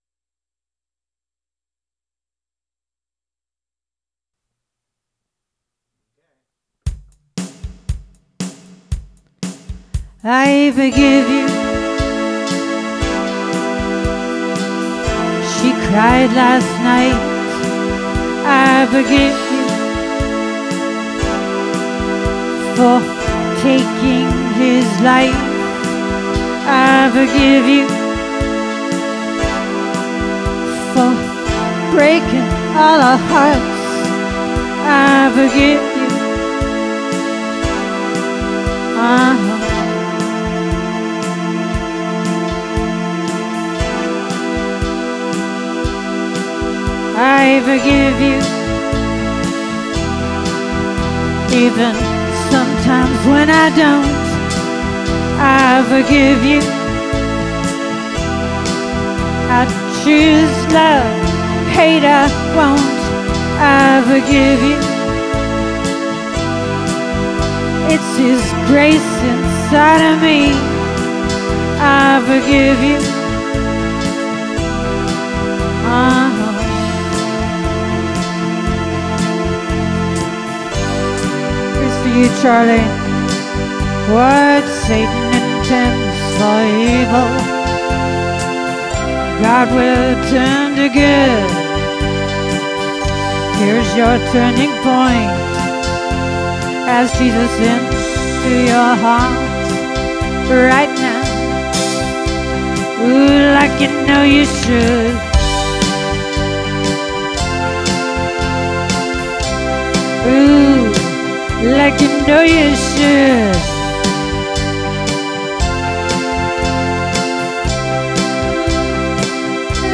Contemporary California Christian Band.